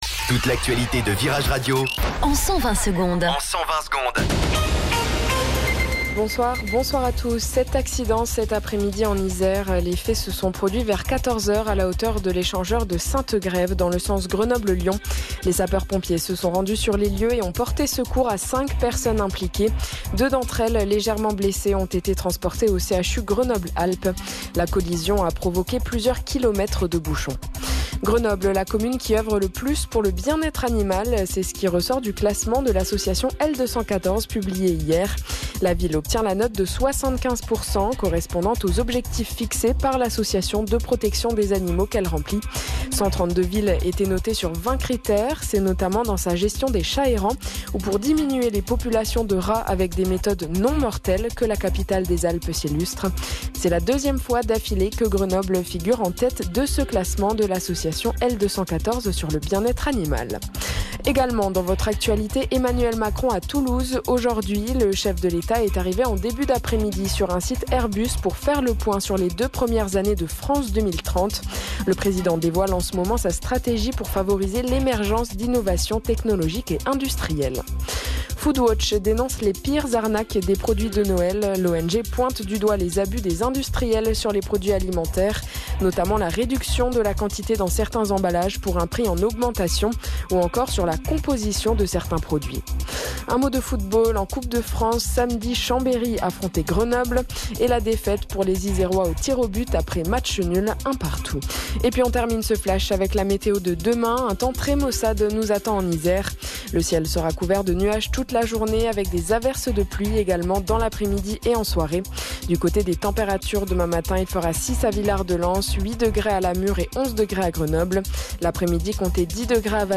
Flash Info Grenoble